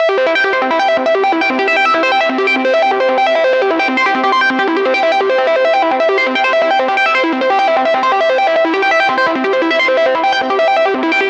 Arplike - Cmaj in 2 octaves .mid
ra_170bpm_Cmaj_oct2-3__iimg.wav